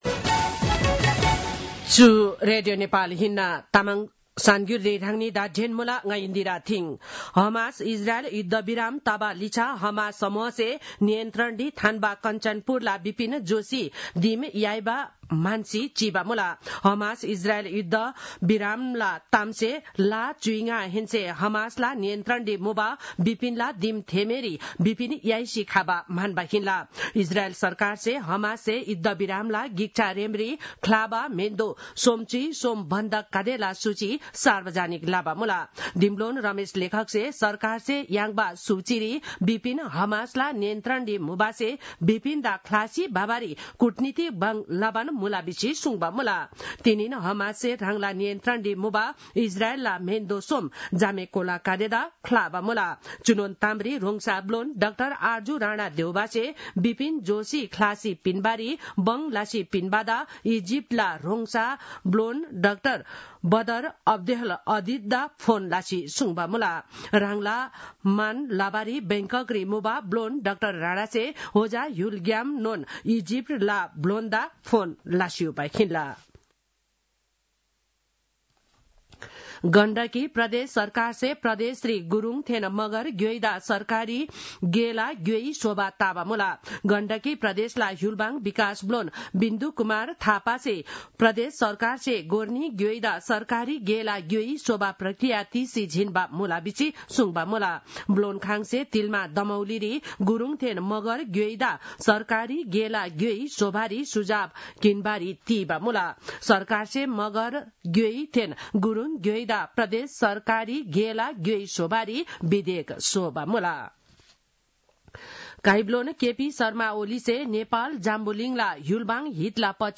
तामाङ भाषाको समाचार : ८ माघ , २०८१
Tamang-news-10-07.mp3